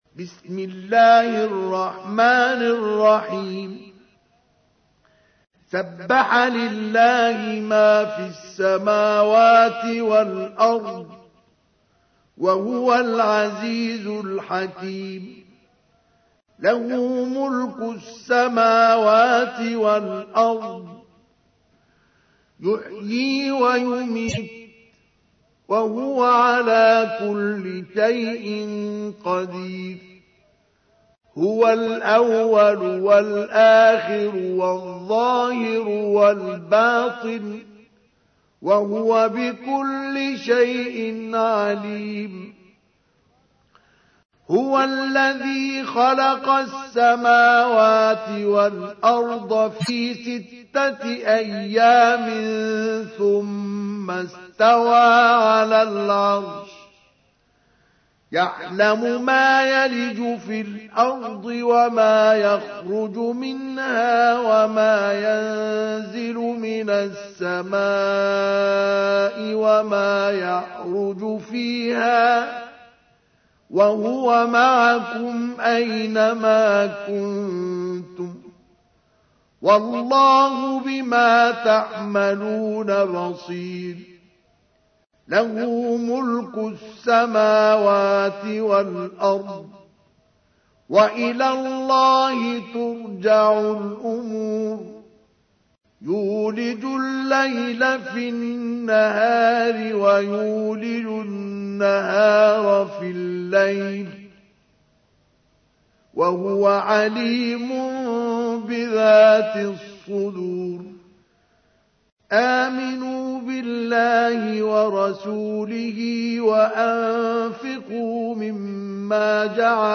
تحميل : 57. سورة الحديد / القارئ مصطفى اسماعيل / القرآن الكريم / موقع يا حسين